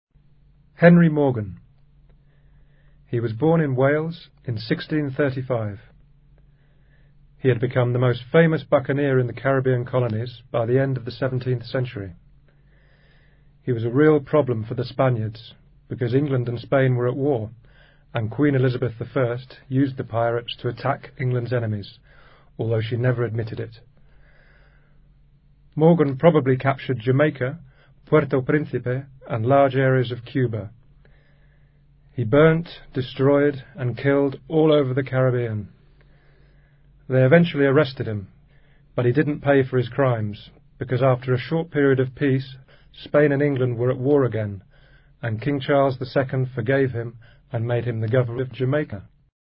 Se narra la biografía de Henry Morgan, aventurero inglés nacido en Gales en 1635. Henry Morgan fue elegido almirante de los bucaneros en 1666.